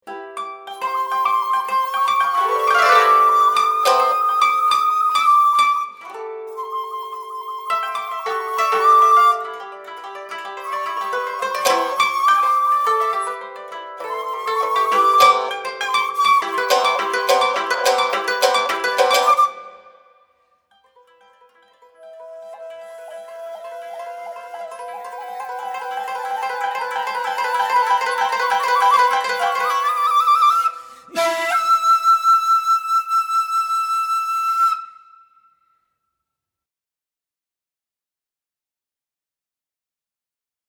尺八、三味線、十三絃箏 (Shakuhachi, Shamisen, 13-string koto)
この曲においては、曲頭の空間的な響きの要素と、それに続くリズミックな動きを伴う要素が交互に現れる。